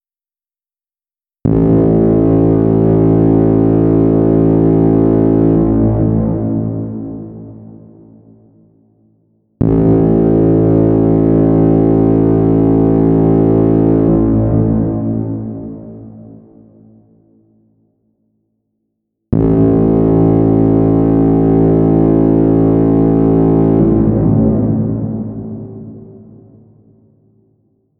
Despertador
Alarm Alarm-Clock Bedroom dawn early ennoying morning sunrise sound effect free sound royalty free Sound Effects